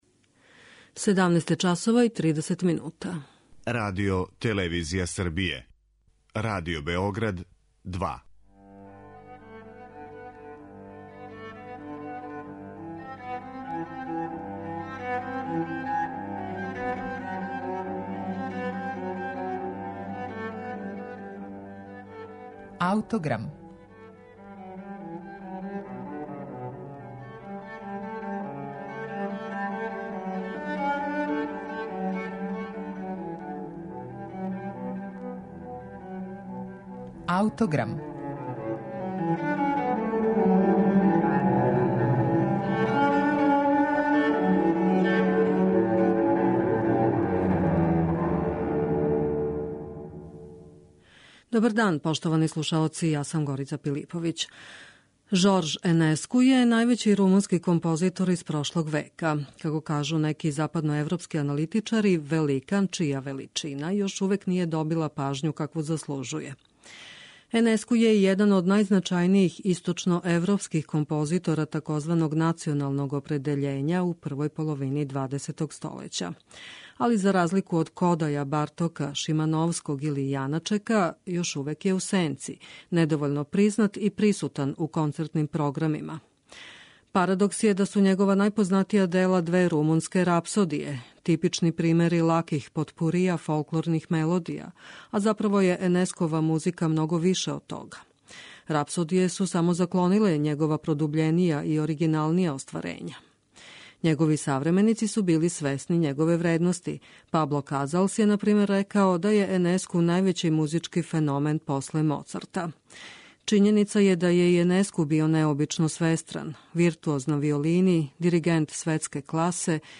Жорж Енеску - Клавирски квартет